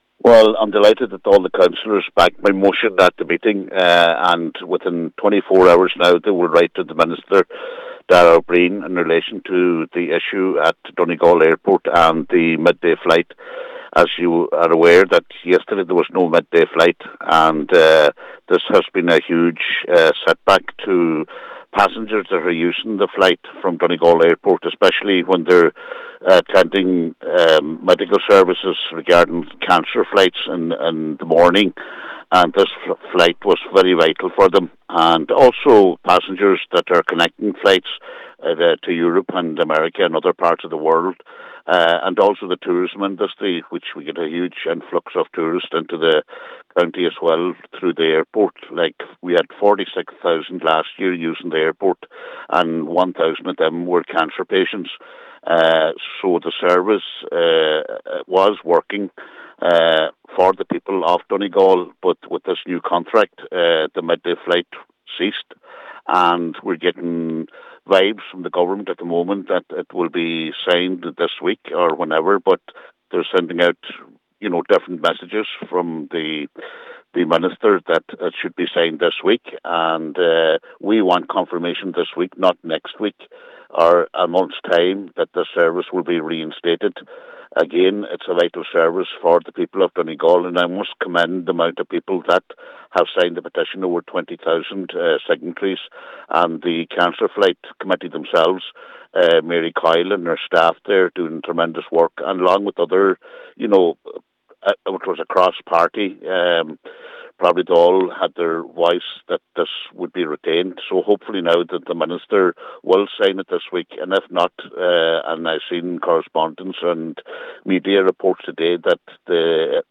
Meanwhile a plenary meeting of Donegal County Council has agreed to write to Minister O’Brien to echo the calls being made by Donegal Cancer Flights and Services.
Cllr John Sheamais O’Fearraigh put forward the motion: